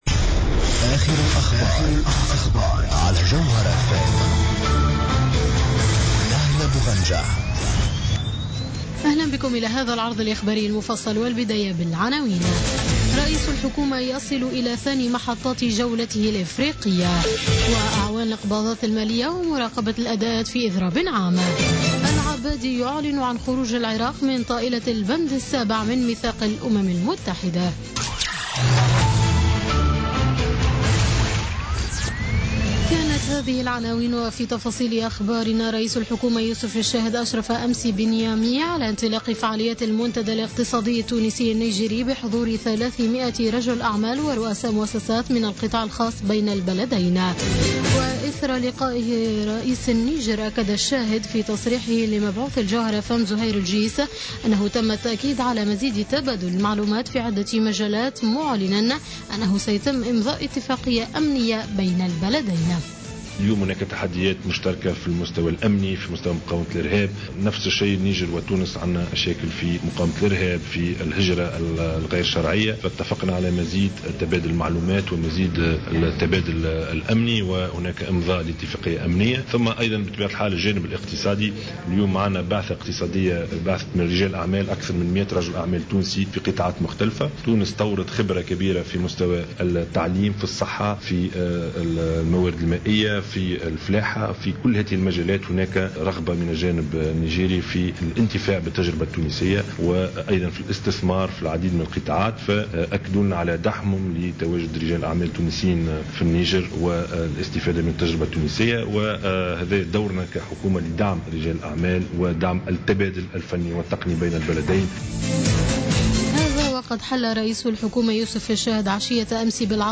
نشرة أخبار منتصف الليل ليوم الاربعاء 5 أفريل 2017